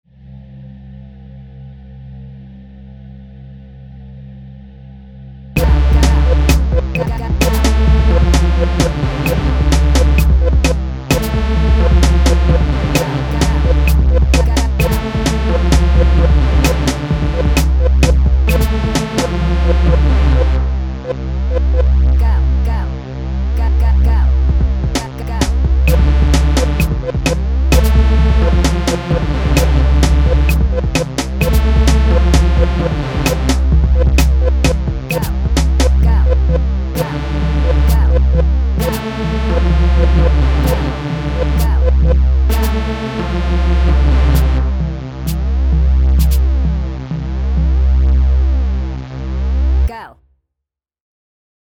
beat for sale 1000 dolalrs….mp3(1.18 MB, Download)
some random beat. Thoughts?